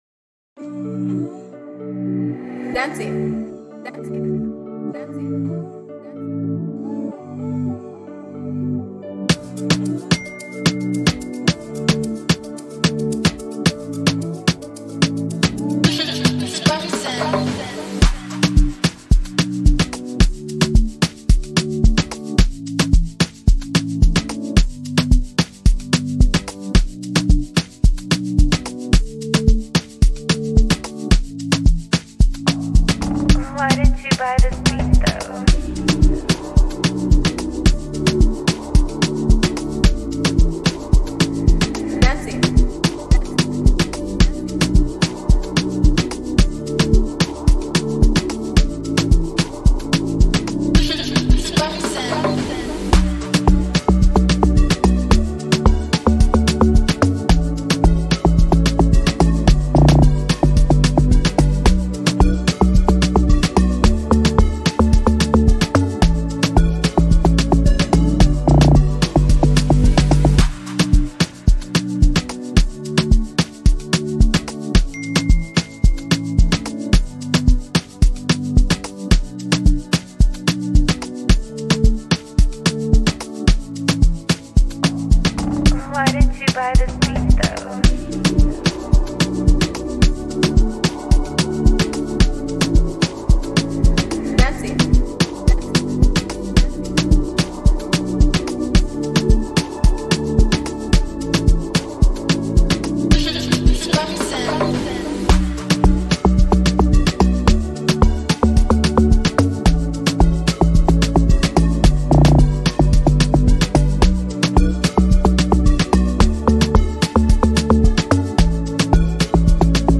Sad Emotional Afrobeat instrumental